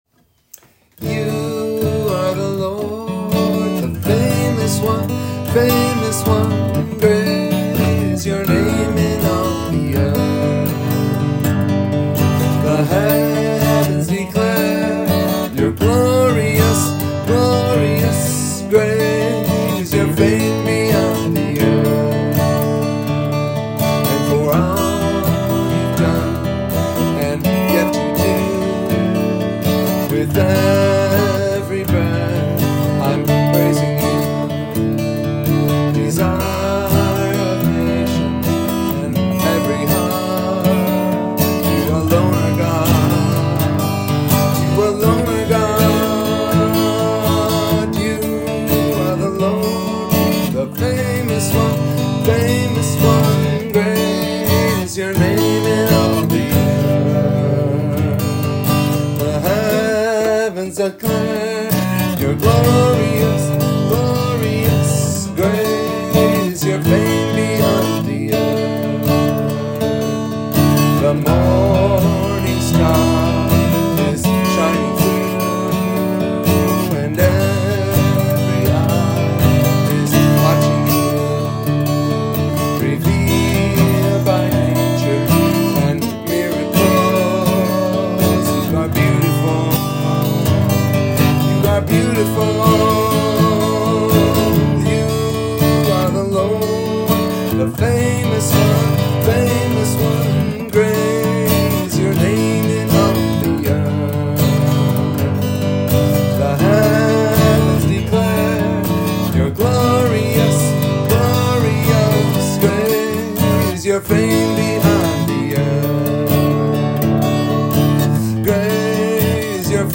Transpose from D